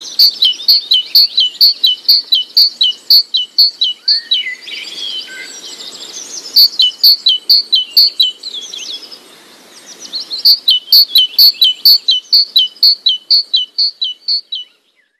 Meise Vogel Klingelton Kostenlos
Kategorien Tierstimmen